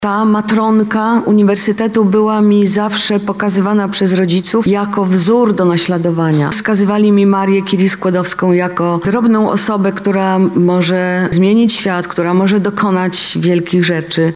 Anne Applebaum, Agnieszka Holland i Olga Tokarczuk okolicznościowe dyplomy odebrały podczas uroczystości, która odbyła się wczoraj w auli uniwersyteckiej na Wydziale Prawa.